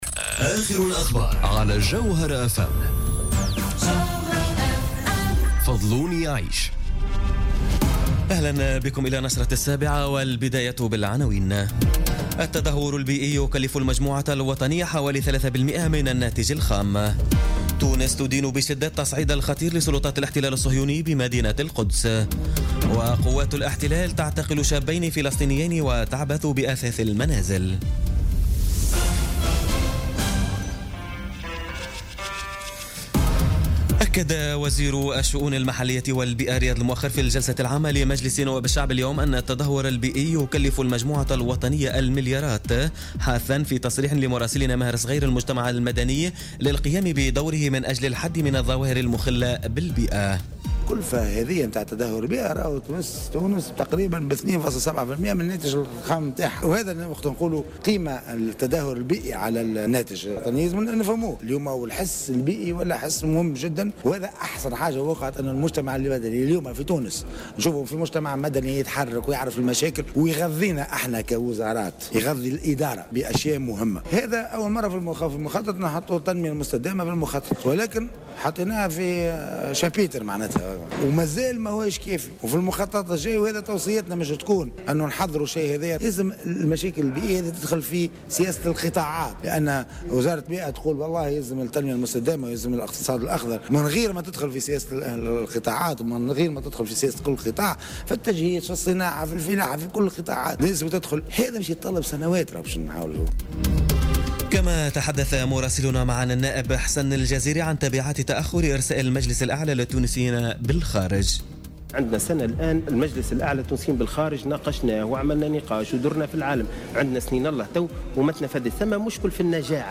نشرة أخبار السابعة مساء ليوم السبت 22 جويلية 2017